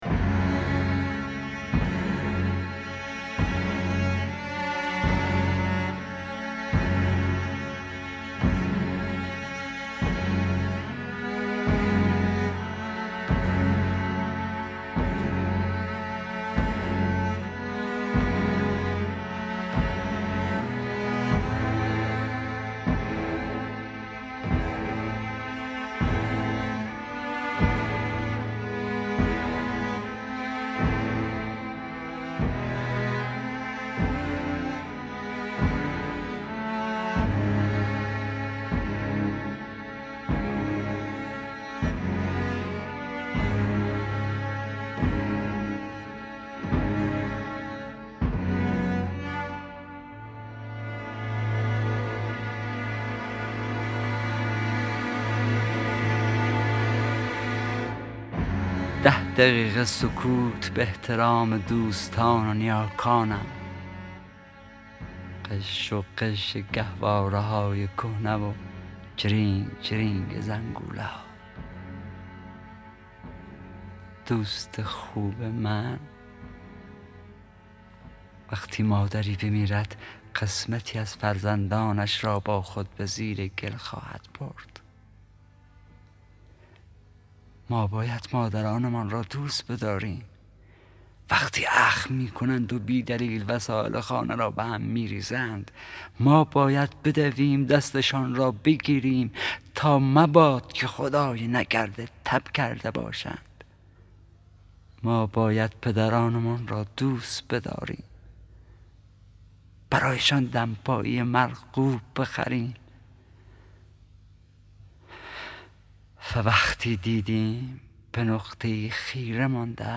دانلود دکلمه ده دقیقه سکوت با صدای حسین پناهی با متن دکلمه
گوینده :   [حسین پناهی]